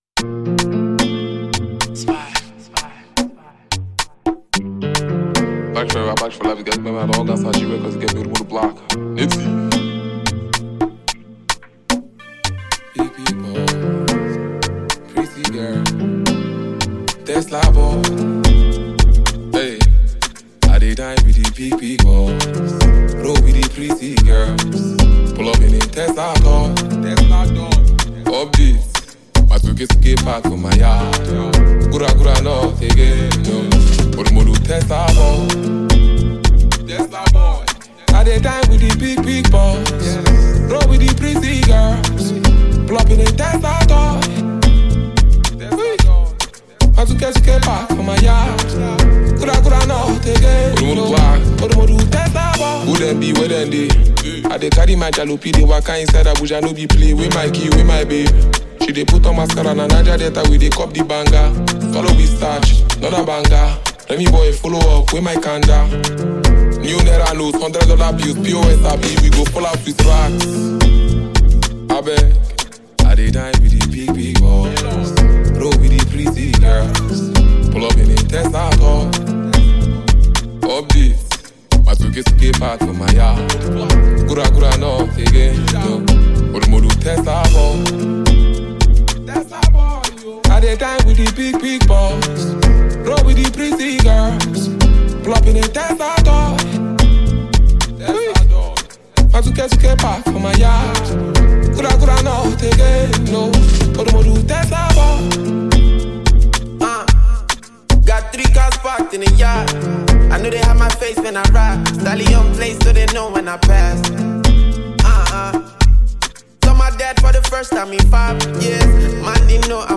Phenomenon talented Nigerian rap artist and performer